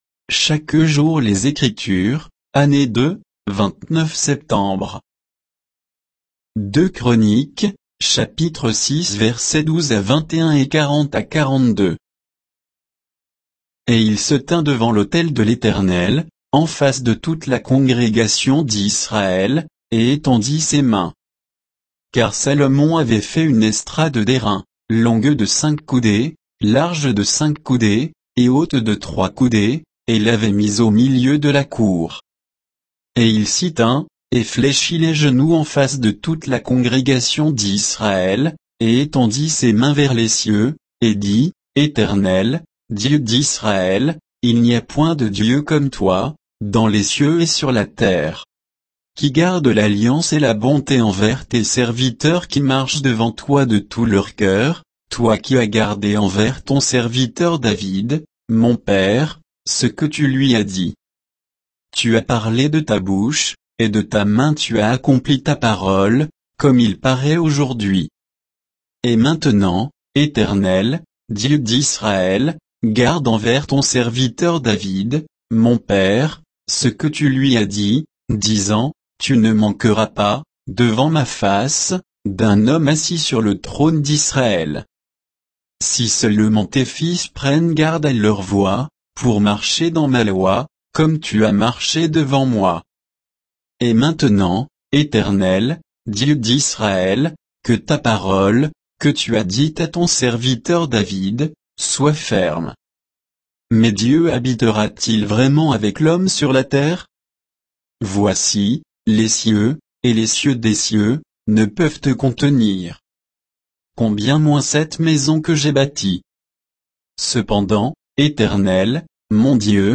Méditation quoditienne de Chaque jour les Écritures sur 2 Chroniques 6, 12 à 21, 40 à 42